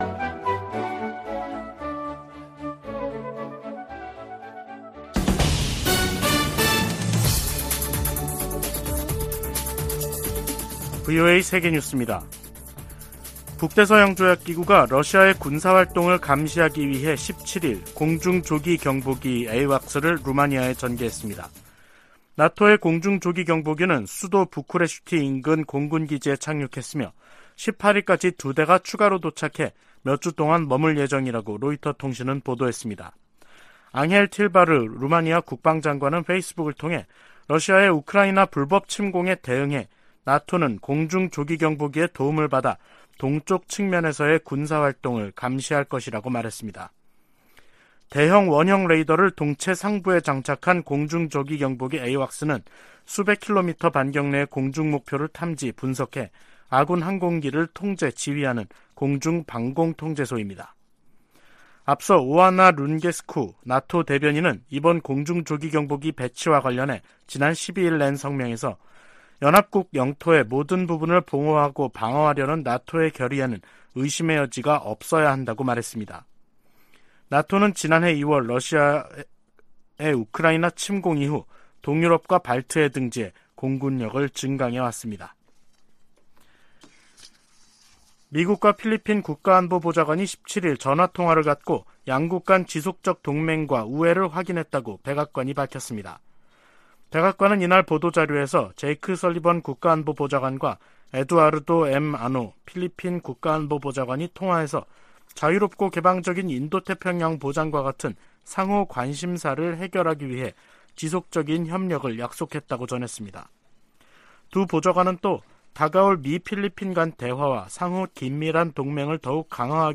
VOA 한국어 간판 뉴스 프로그램 '뉴스 투데이', 2023년 1월 18일 3부 방송입니다. 미 국방부는 로이드 오스틴 국방장관이 곧 한국을 방문할 것이라고 밝혔습니다. 한국 통일부가 올해 북한과의 대화 물꼬를 트겠다는 의지를 밝히고 있으나 북한이 호응할 가능성은 크지 않다는 관측이 나오고 있습니다.